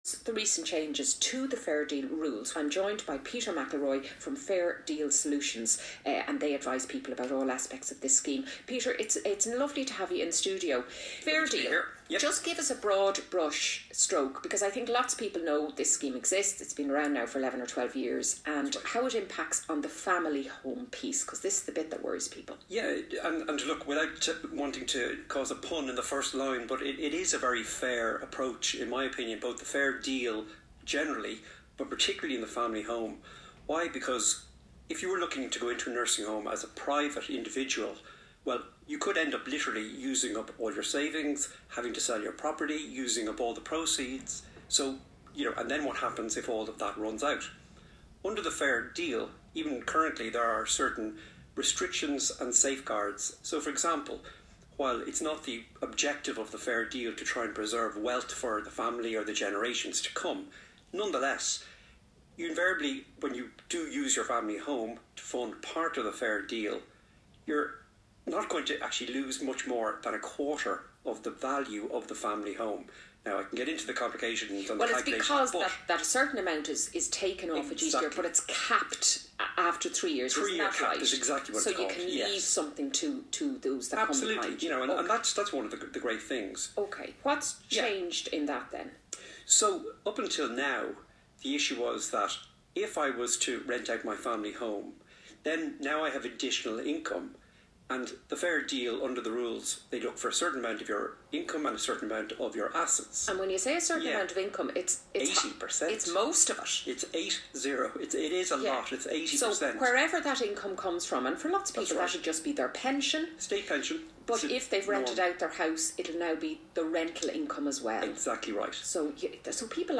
Radio Interviews with Fair Deal Solutions